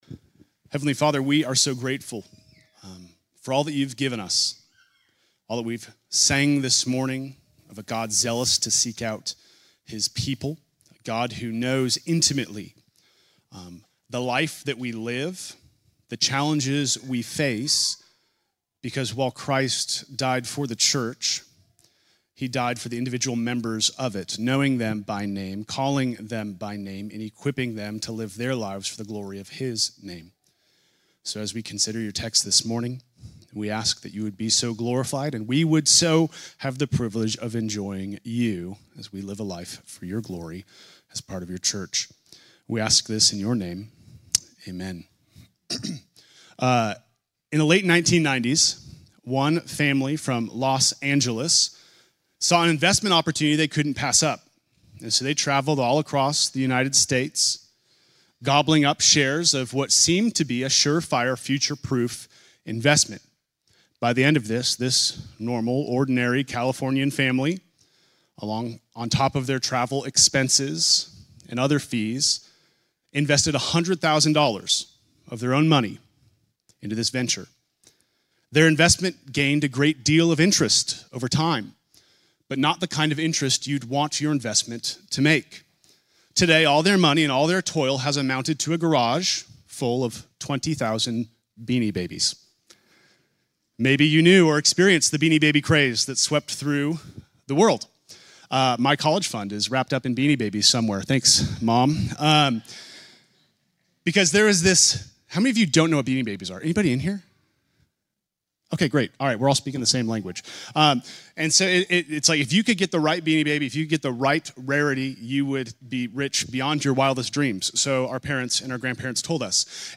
Sunday morning message January 11